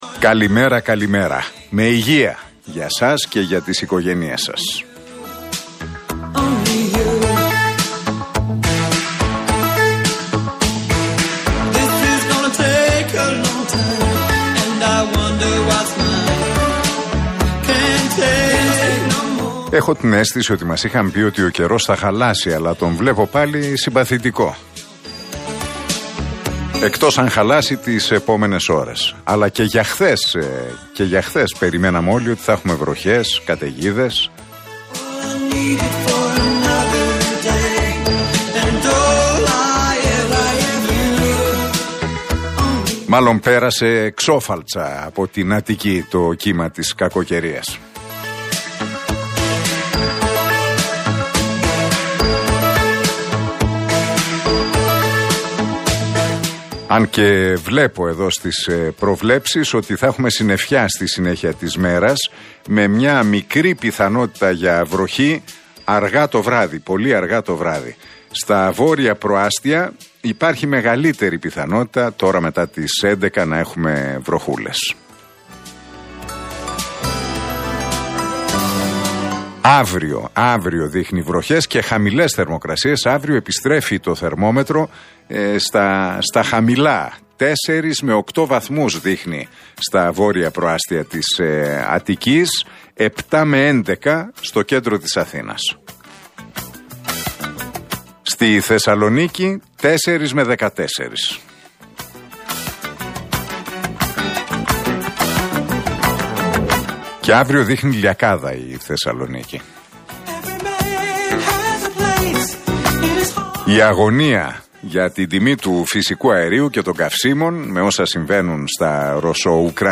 Ακούστε το σημερινό σχόλιο του Νίκου Χατζηνικολάου στον Realfm 97,8.